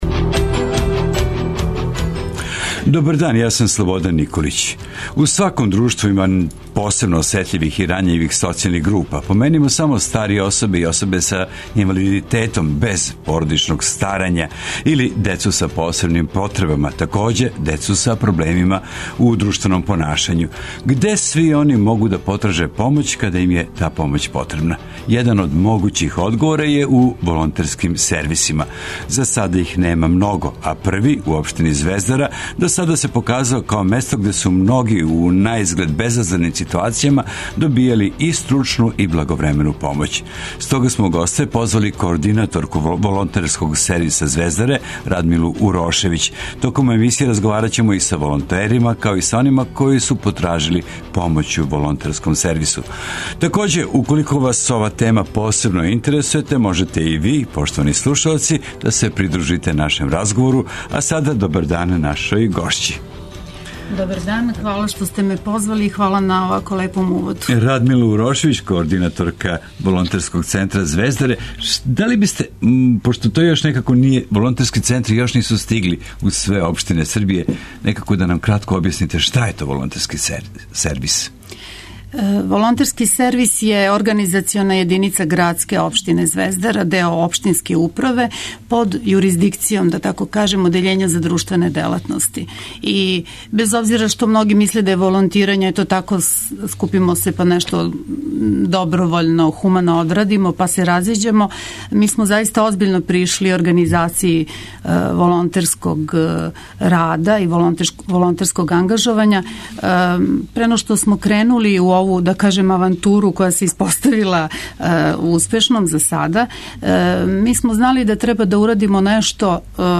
Разговараћемо, такође, и са волонтерима, као и с онима који су потражили помоћ у Волонтерском сервису.